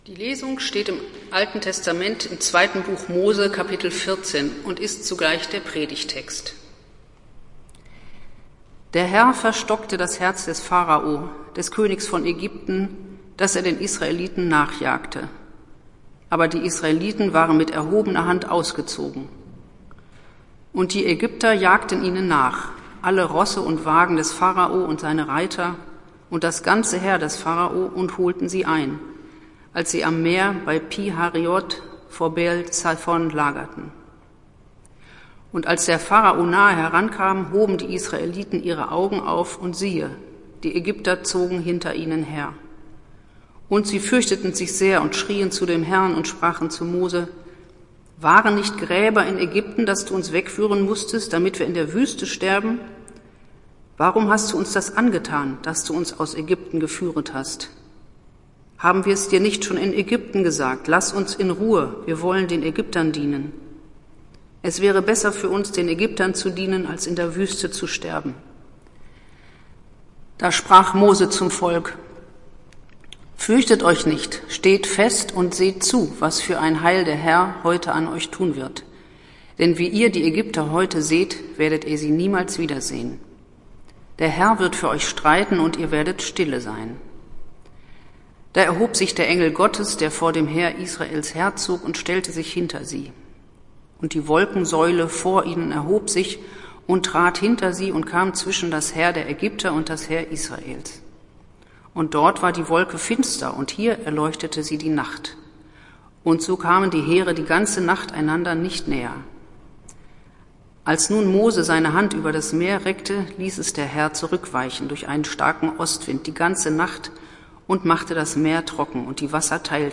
Predigt des Gottesdienstes aus der Zionskirche zum Ostersonntag, 04.04.2021